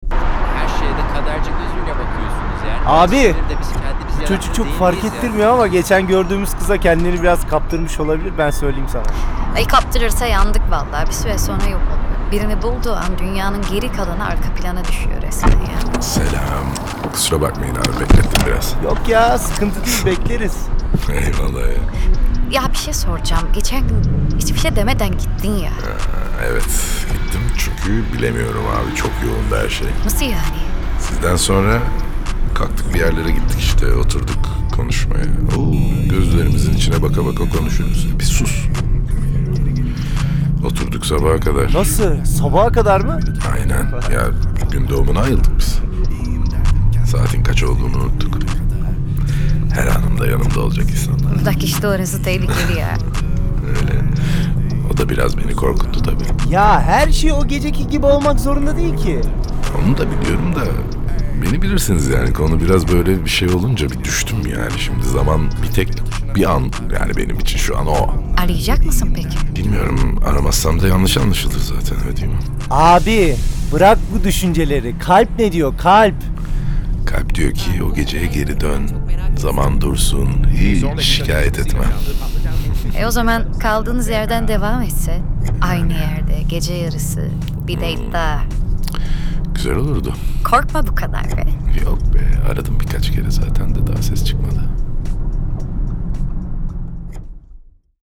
Трек размещён в разделе Турецкая музыка / Альтернатива.